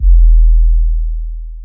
Kicks
DB - Kick (1).wav